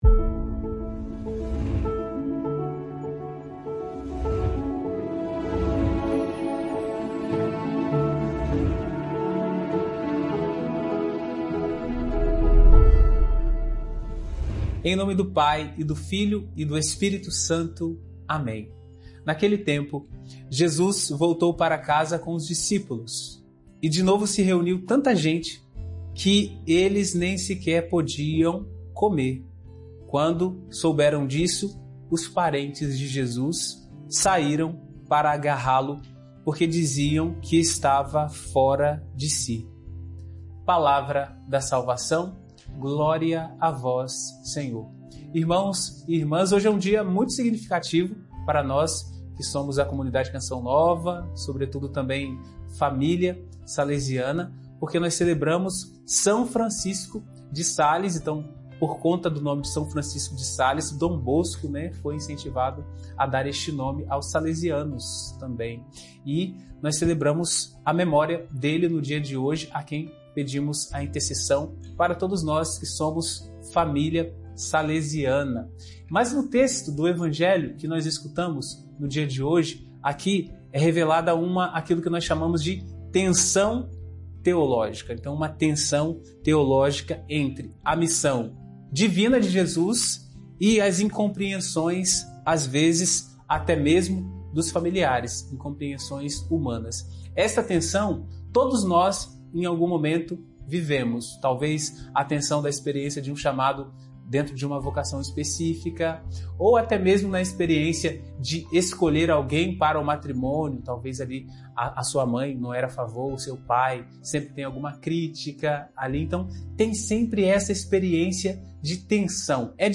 Tensão teológica, reflexão sobre Marcos 3,20-21. Homilia de hoje